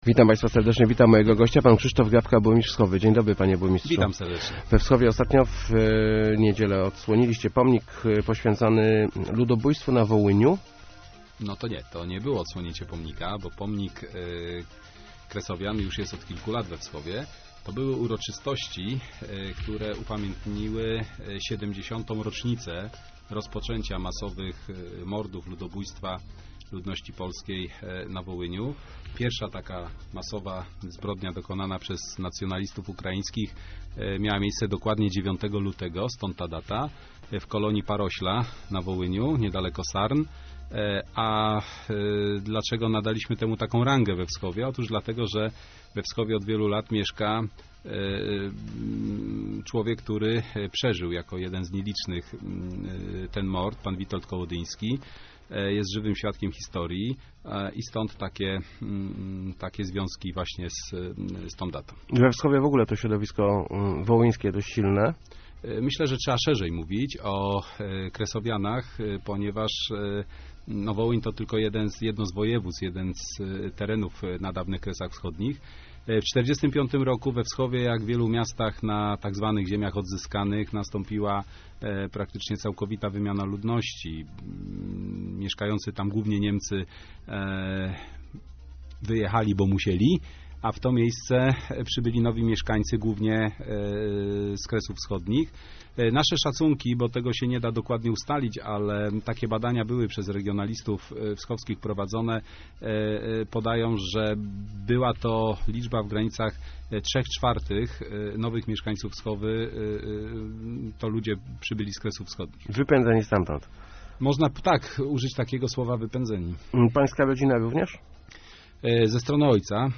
Wierzę w to, że kiedyś prawda zwycięży i stosunki polsko-ukraińskie zostaną uzdrowione - mówił w Rozmowach Elki burmistrz Wschowy Krzysztof Grabka. Wschowianie kultywują pamięć o ukraińskich zbrodniach na Wołyniu w 1943 roku.